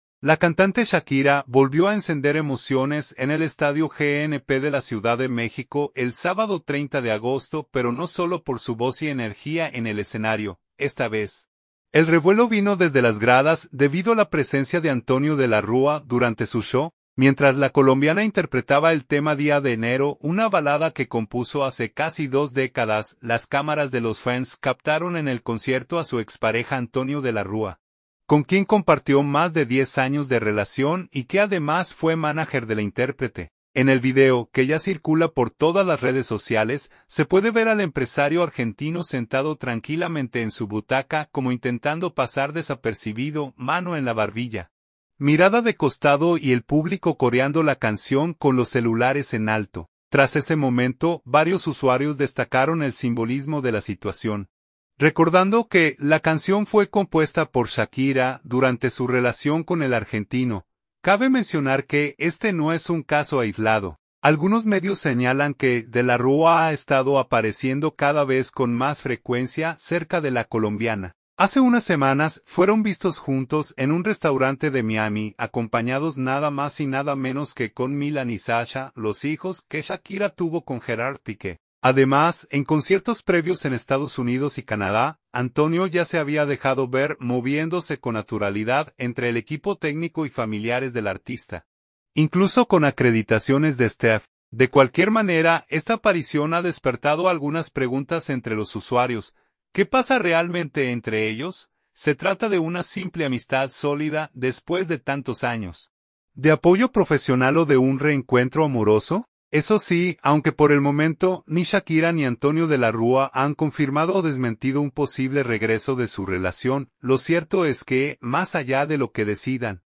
Estadio GNP de la Ciudad de México
una balada que compuso hace casi dos décadas